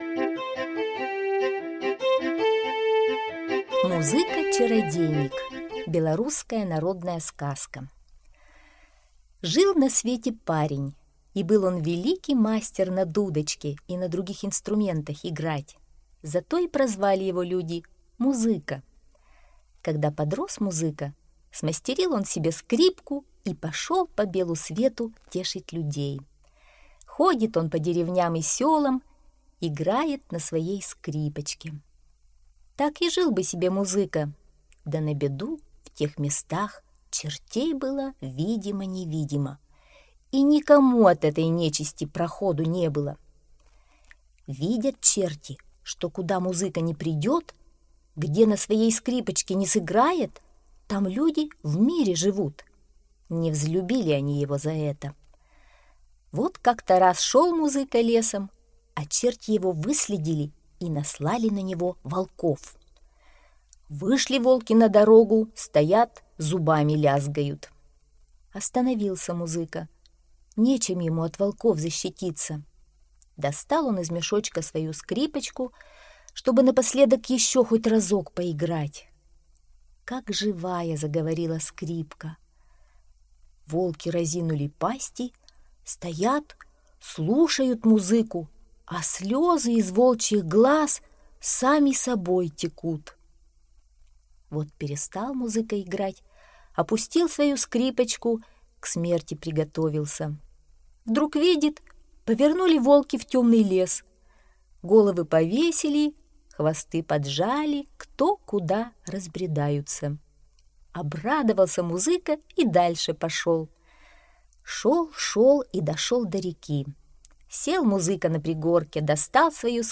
Музыка-чародейник - белорусская аудиосказка - слушать онлайн